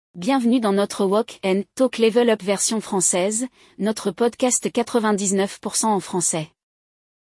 Ouça atentamente ao diálogo e tente entender o contexto antes mesmo da explicação.
A melhor maneira é ouvir e repetir os exemplos em voz alta logo após a professora, imitando o ritmo e a entonação.